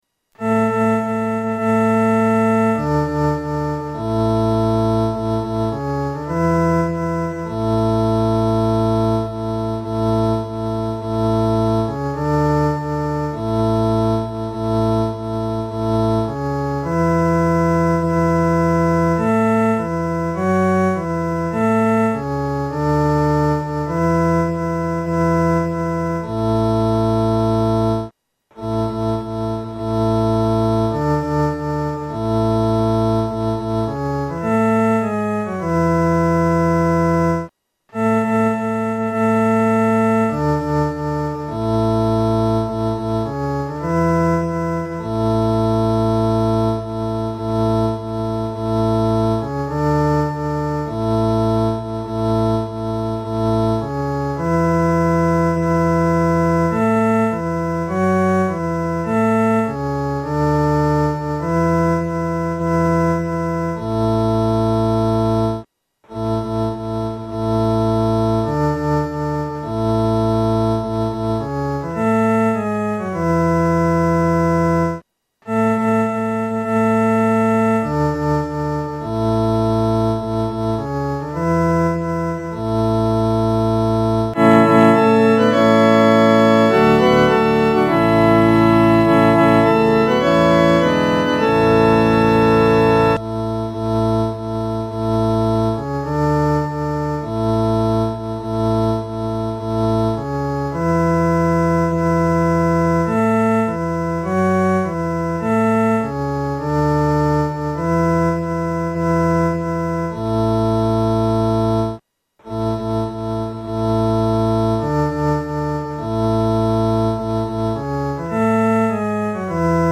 男低
本首圣诗由网上圣诗班 (环球）录制
《颂赞主圣名歌》的正歌曲调比较平稳，但副歌曲调兴奋、活跃。